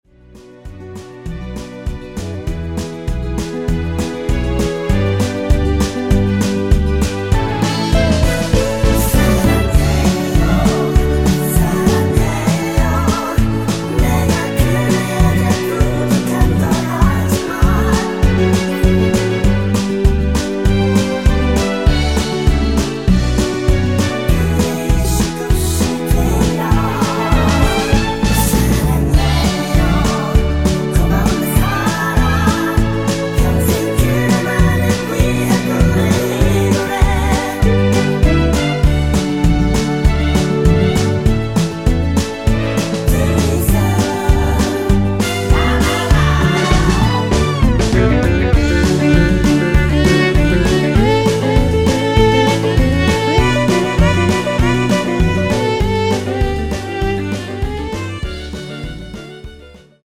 원키에서(+2)올린 코러스 포함된 MR입니다.(미리듣기 확인)
D
앞부분30초, 뒷부분30초씩 편집해서 올려 드리고 있습니다.
중간에 음이 끈어지고 다시 나오는 이유는